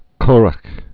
(kûrə, kûrə)